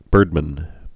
(bûrdmən)